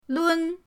lun1.mp3